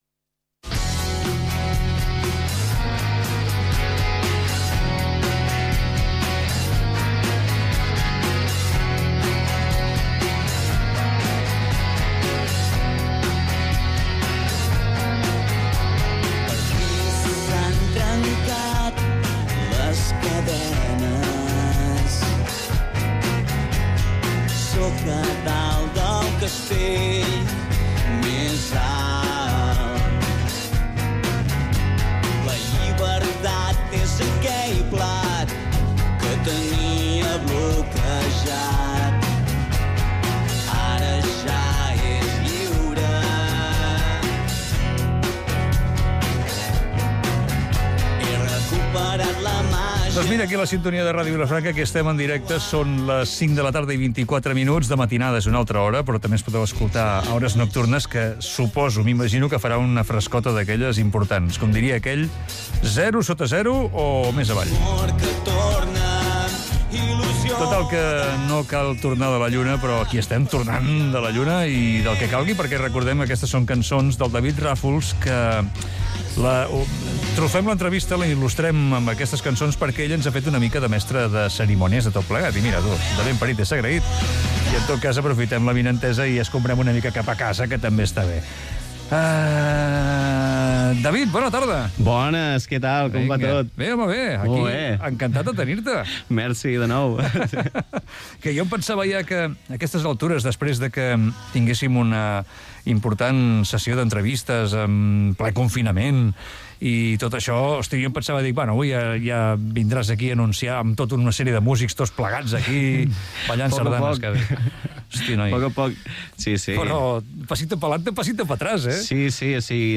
Radiofórmula